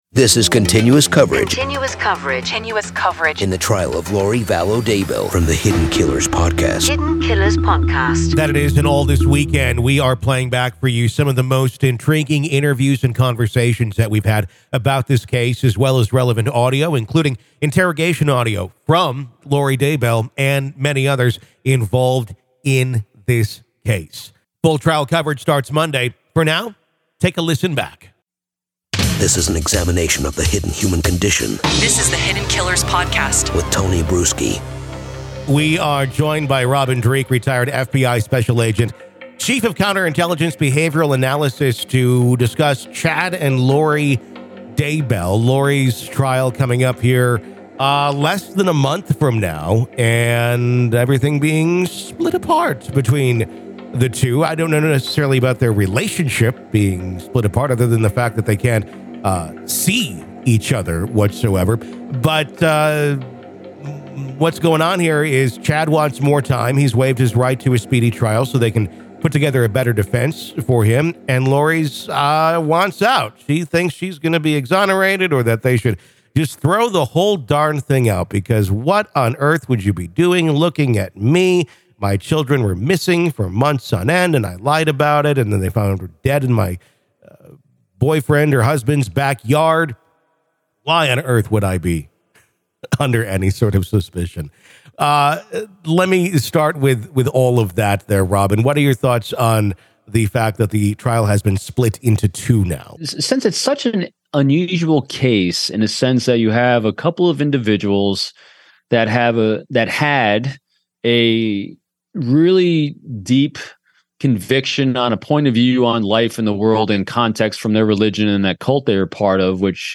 Apr 09, 2023, 05:00 PM Headliner Embed Embed code See more options Share Facebook X Subscribe Join us this weekend on our riveting podcast as we journey through the most captivating interviews and enthralling audio snippets that delve into the enigmatic case against Lori Vallow Daybell.